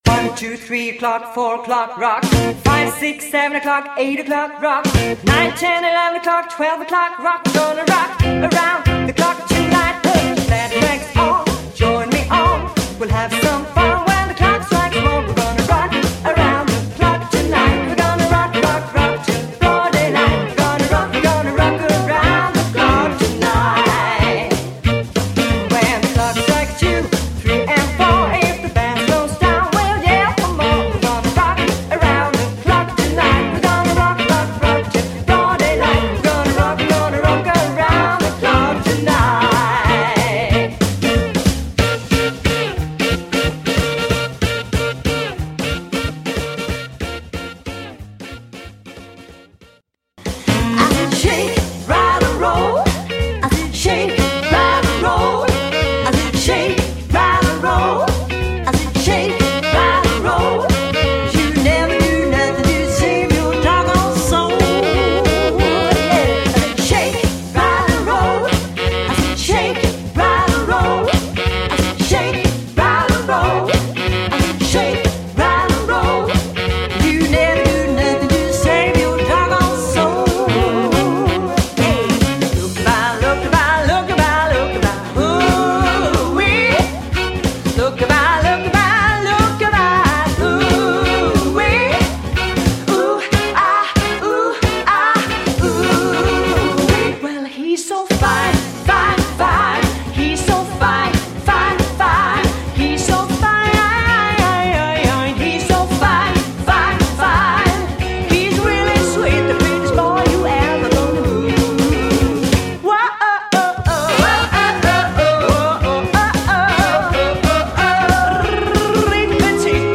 • Duo/trio